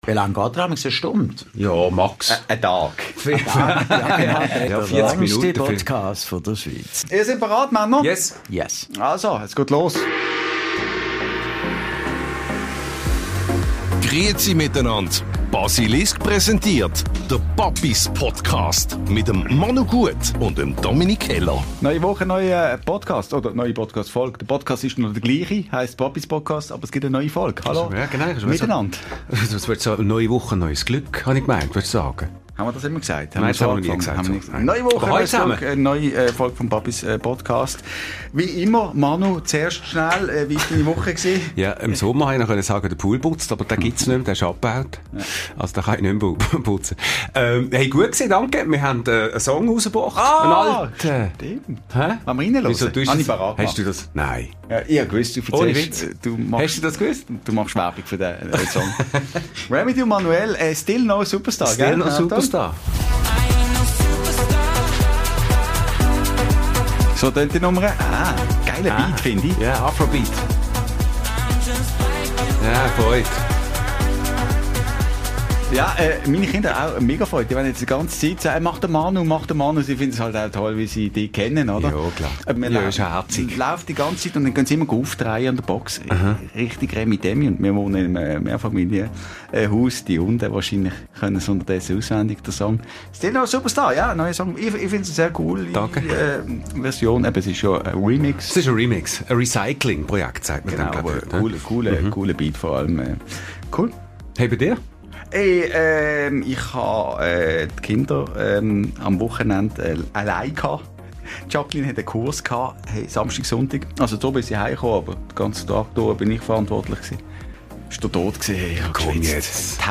Unsere Papis dürfen in der Folge #22 Claudio Zuccolini in der Therapierunde empfangen.